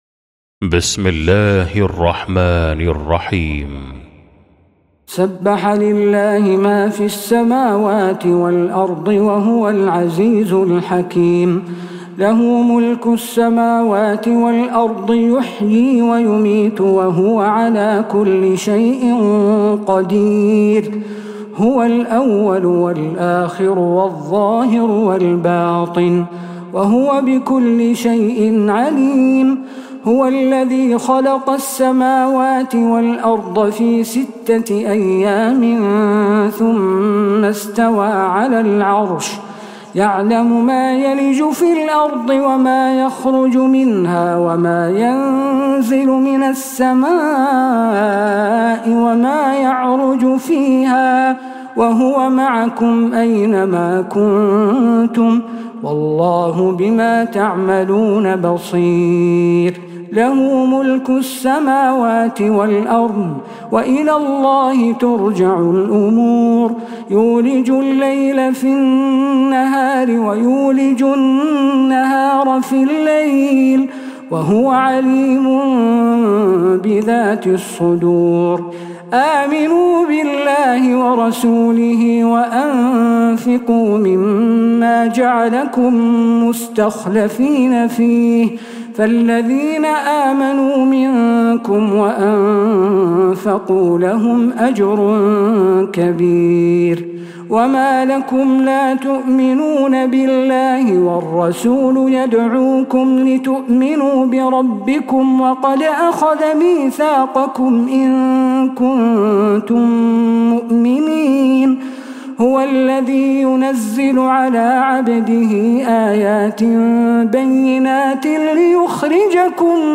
سورة الحديد Surah Al-Hadid > مصحف تراويح الحرم النبوي عام 1446هـ > المصحف - تلاوات الحرمين